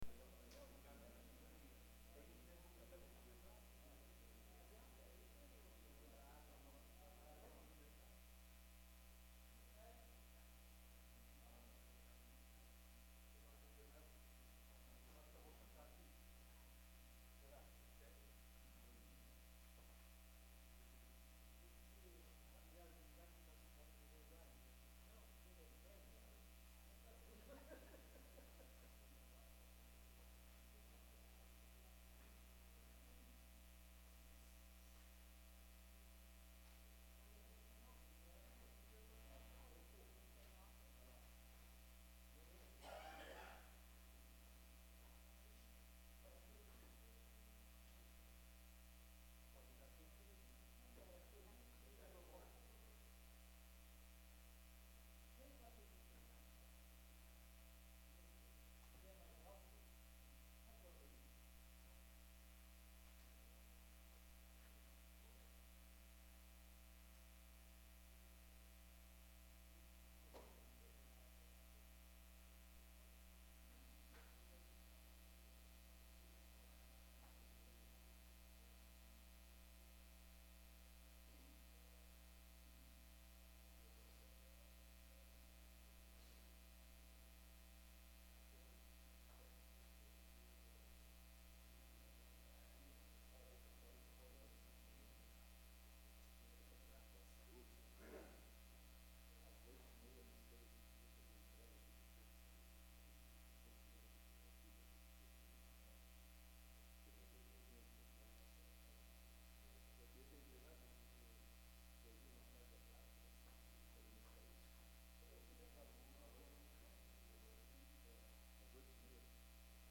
Áudio da Sessão Ordinária de 14 de agosto de 2017